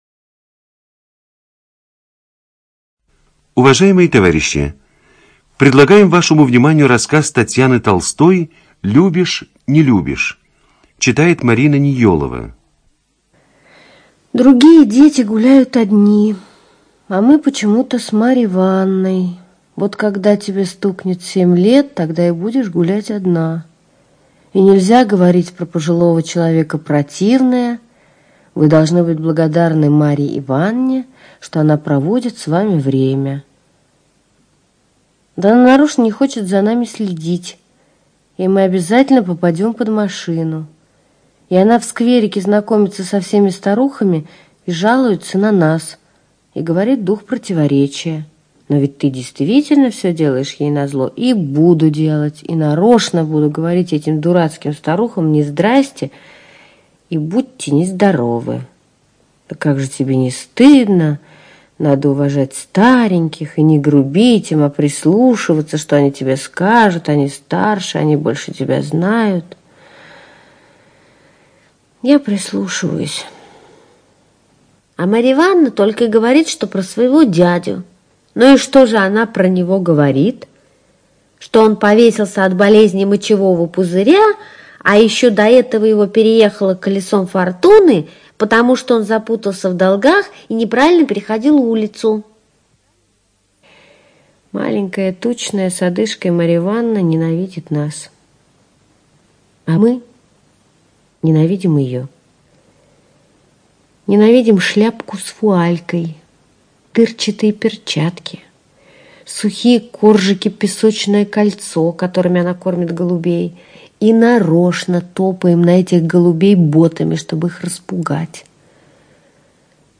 ЧитаетНеёлова М.